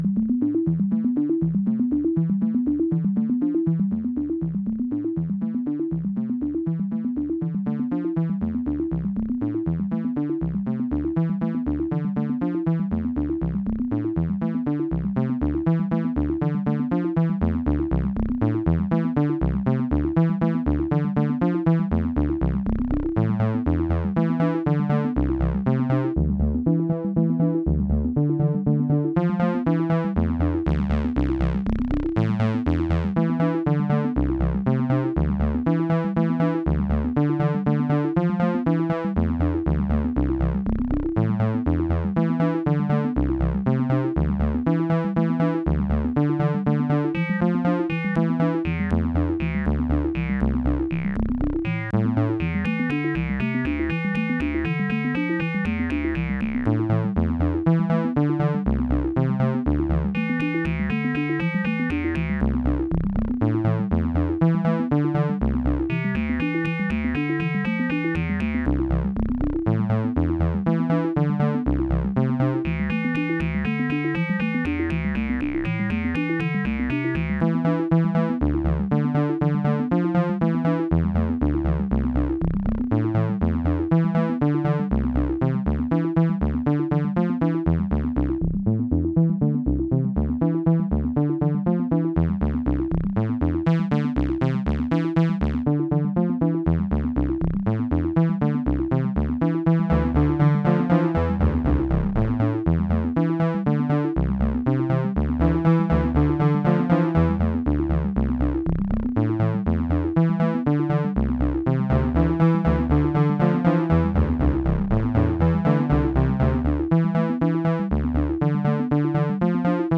Cut from a live coding session in SonicPi.
It’s just some scaling, some notes and two synths (tb303, detuned saw waves), but somehow reminds me of 80’s songs.